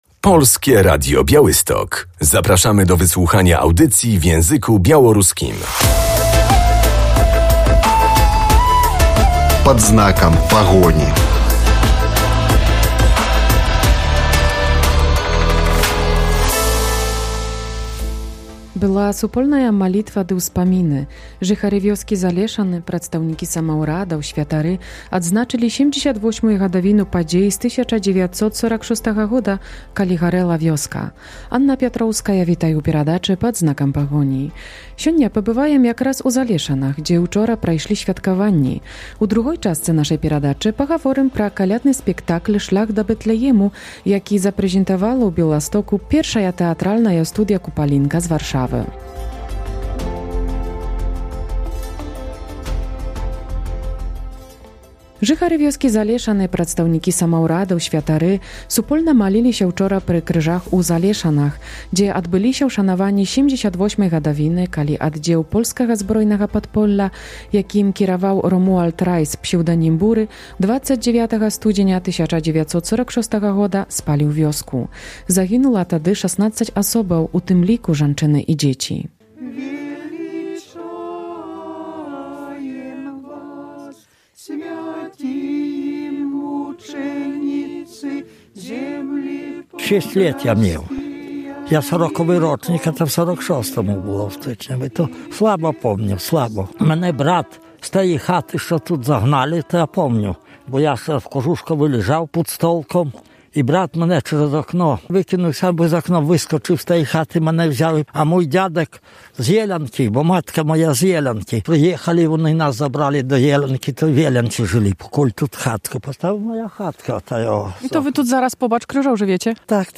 W audycji będziemy na uroczystościach 78. rocznicy pacyfikacji wsi Zaleszany.